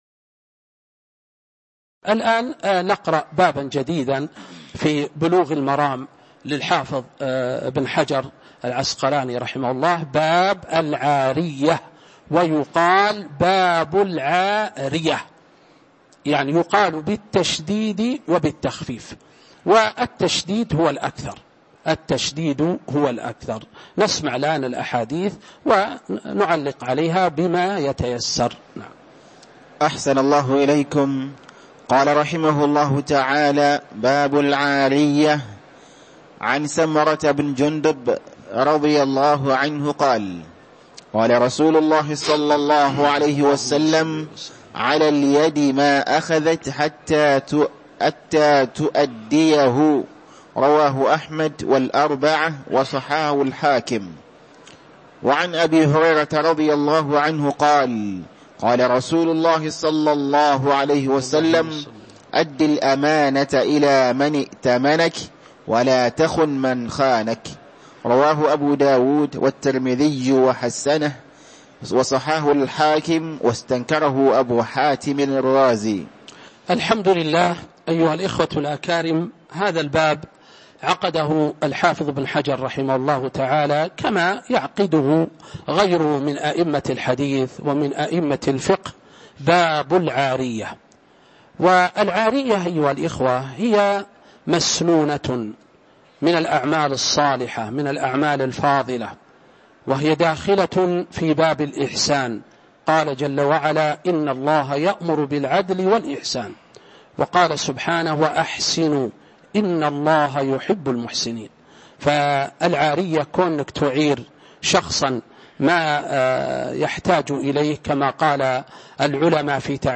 تاريخ النشر ٢٩ جمادى الأولى ١٤٤٦ هـ المكان: المسجد النبوي الشيخ